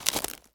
wood_tree_branch_move_03.wav